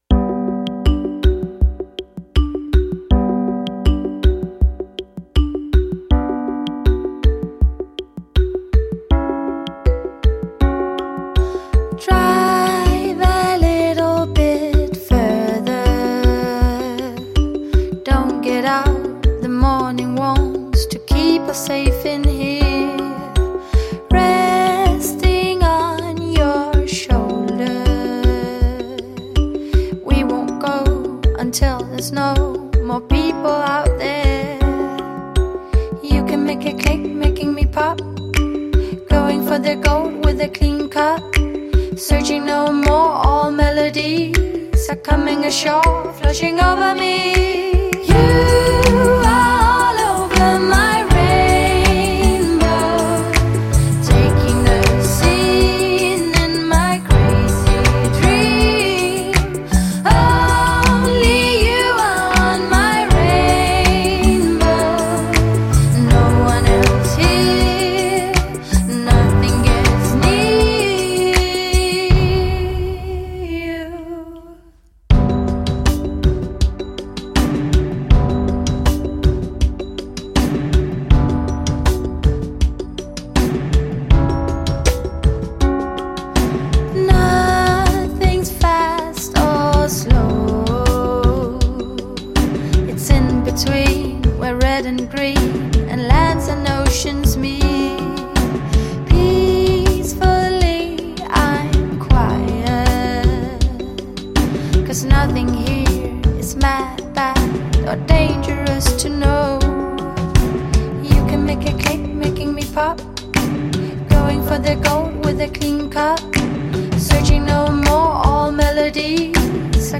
Danish singer-songwriter